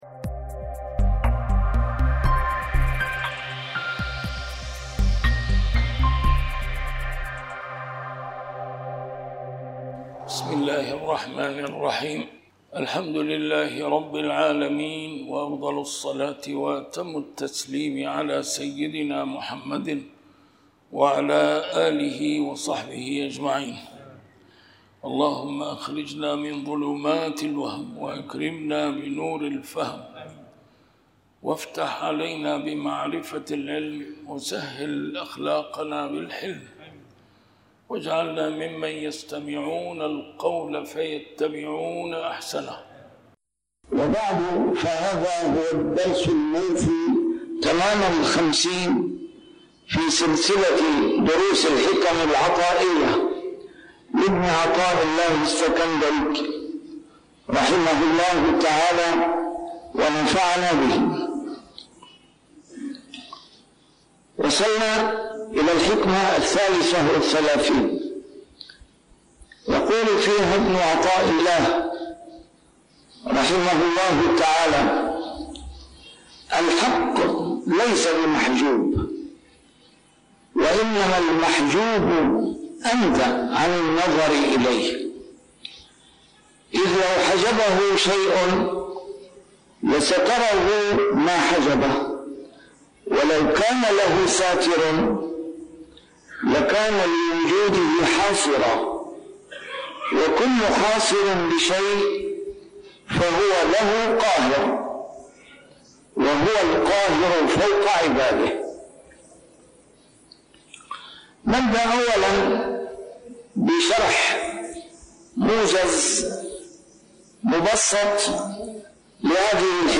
A MARTYR SCHOLAR: IMAM MUHAMMAD SAEED RAMADAN AL-BOUTI - الدروس العلمية - شرح الحكم العطائية - الدرس رقم 50 شرح الحكمة 33